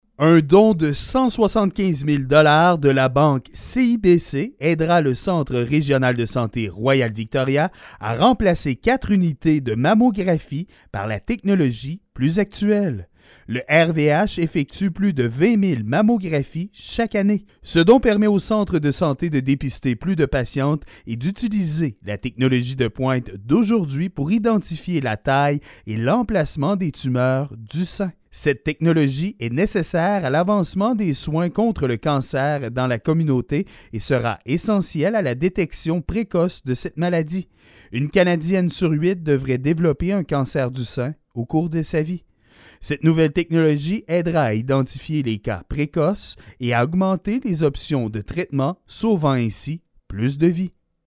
Voici les explications de notre journaliste: